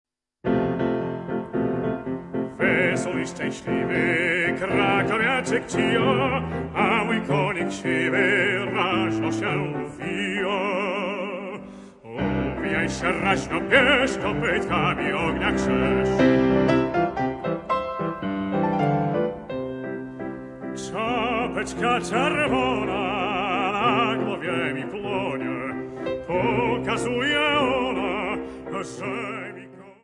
Fortepian